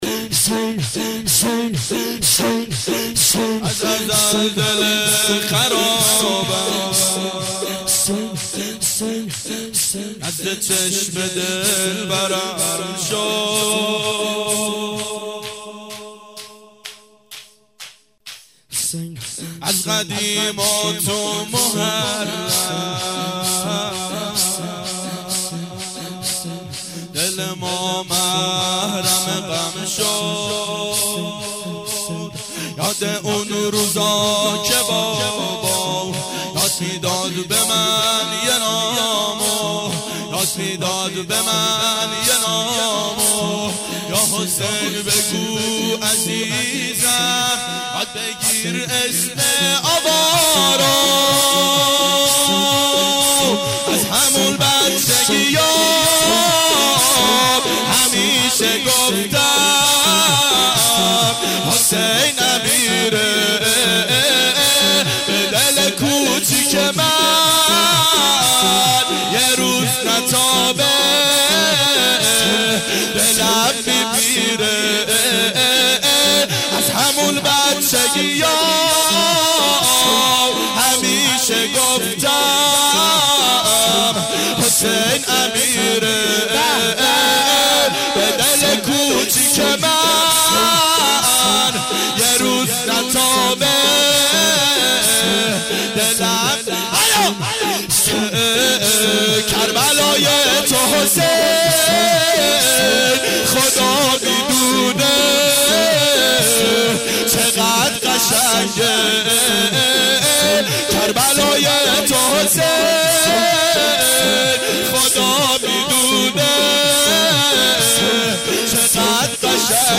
ظهر اربعین سال 1388 محفل شیفتگان حضرت رقیه سلام الله علیها